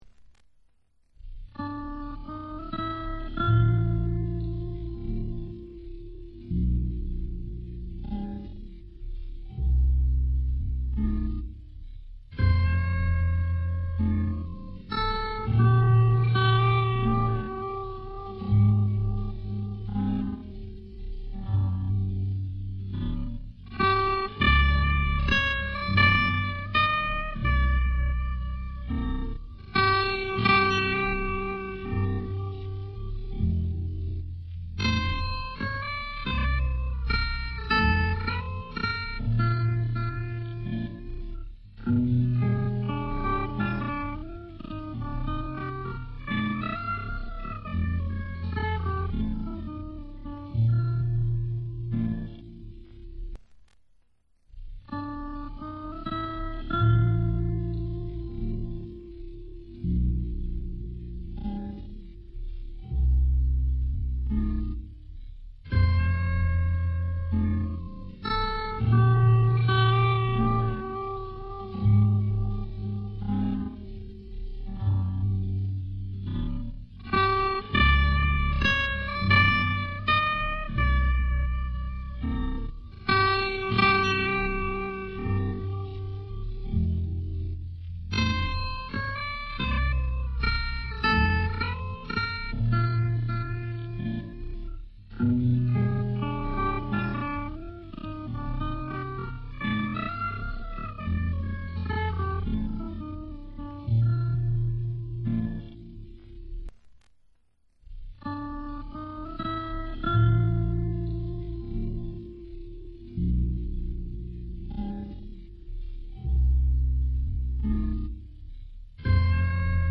voilà un autre ralentie qui me sert beaucoup pour le placement et pour ne pas oublier de notes car pour le moment j'essaye d'apprendre sans tab comme les vrais :P
d'accord avec toi ;) saut que j'entends un glissé entre le 8 et le 10 sur la corde de sol